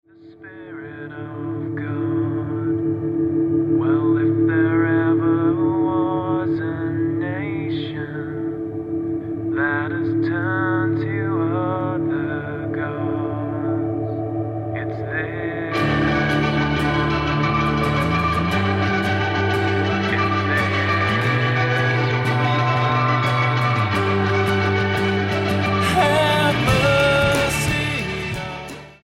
STYLE: Pop
is an intense call for repentance building nicely